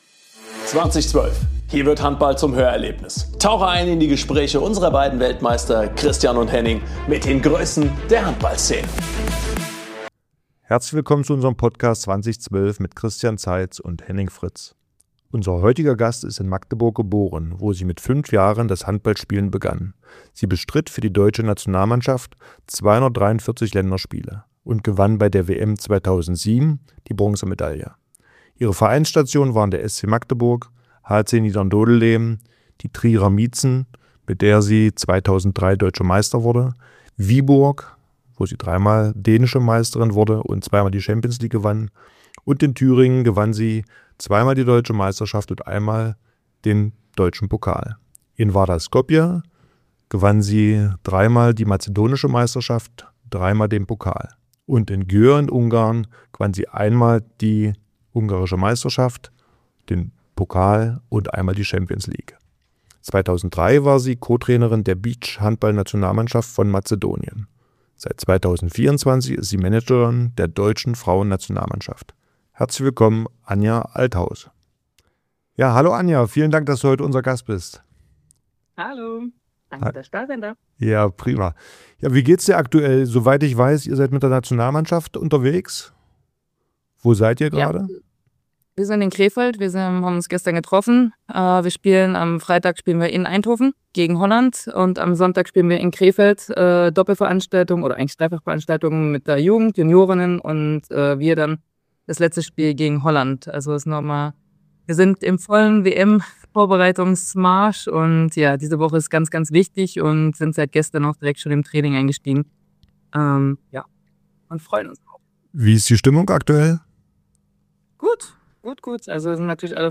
Christian Zeitz und Henning Fritz sprechen mit Anja über die Höhepunkte ihrer einzigartigen Laufbahn, den Weg von einer Weltklassespielerin zur erfolgreichen Managerin, ihre Rolle im deutschen Handball und die bevorstehende WM im eigenen Land.